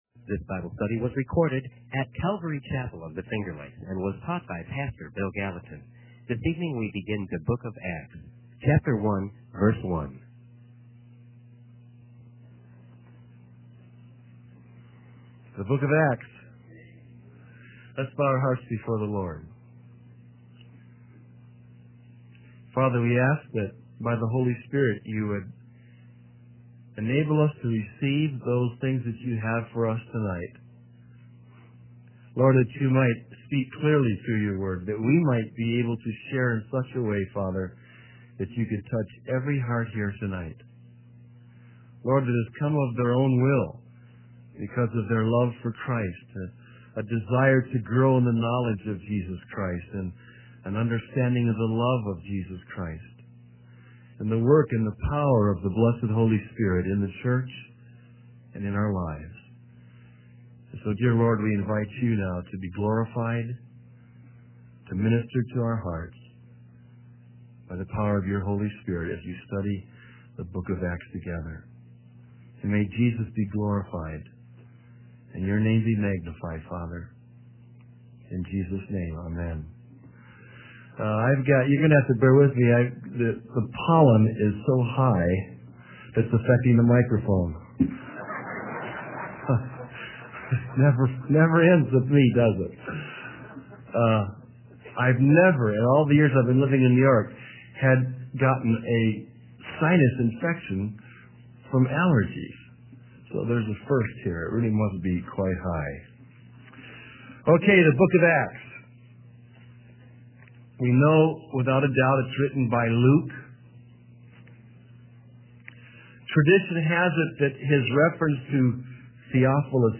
In this sermon, the preacher emphasizes the unshakable security that believers have in Jesus Christ. He highlights that nothing can hinder God's plan of redemption and the establishment of His kingdom.